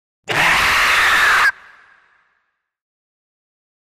Alien Screech Scream 5 - Monster Dinosaur